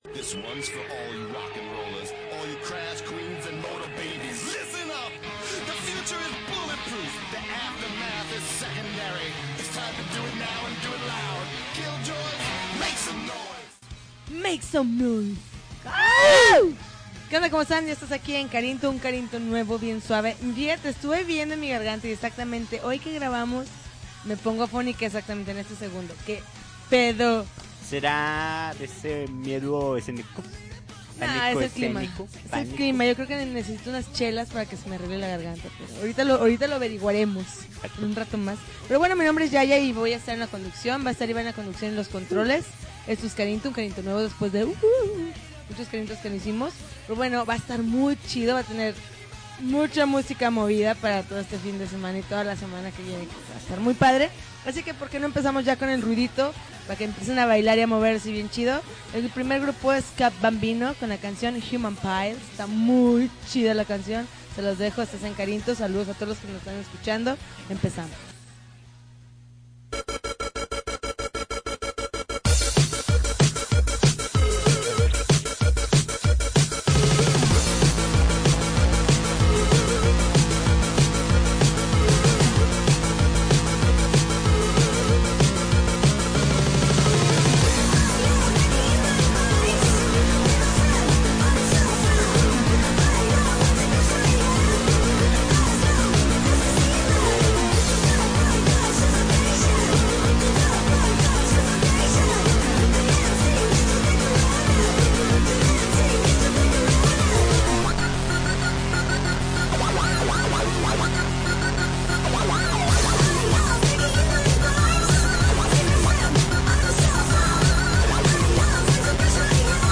November 21, 2010Podcast, Punk Rock Alternativo